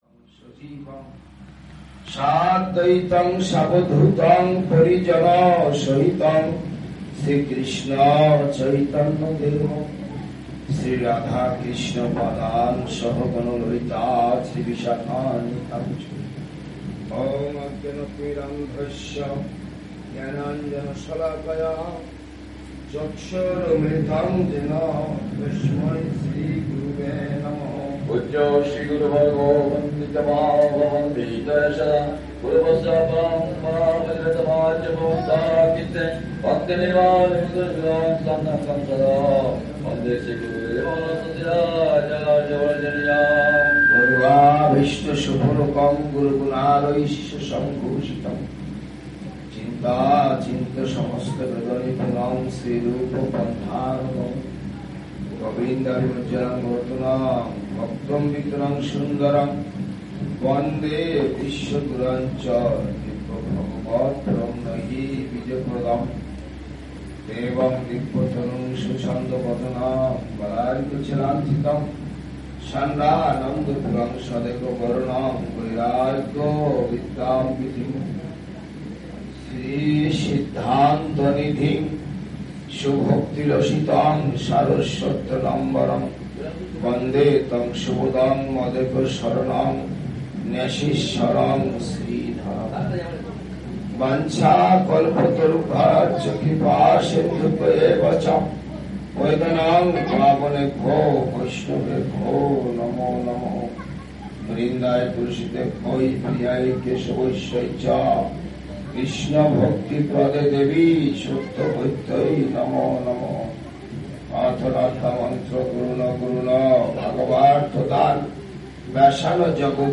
Preaching programme in Hooghly district
LECTURE